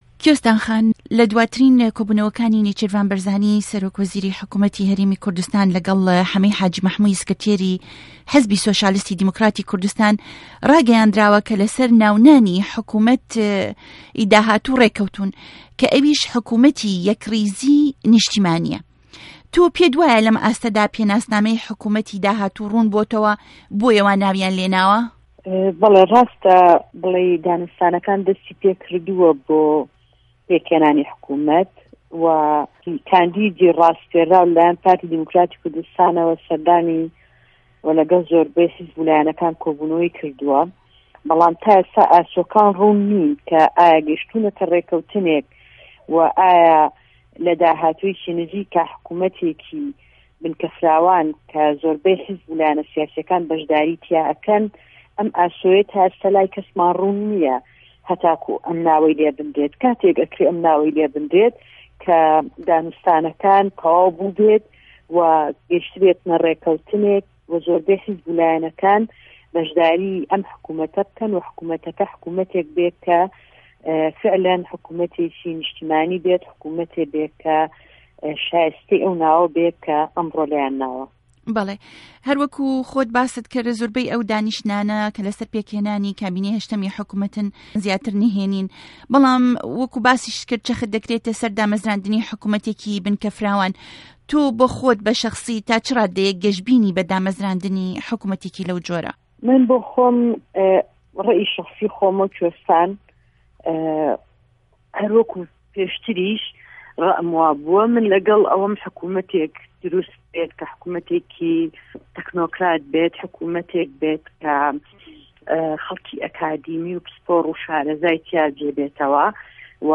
هه‌رێمه‌ کوردیـیه‌کان - گفتوگۆکان
گفتوگۆ ڵه‌که‌ڵ کۆیستان محه‌ممه‌د 3ی مانگی 12ی ساڵی 2013